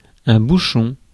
Ääntäminen
IPA: [bu.ʃɔ̃]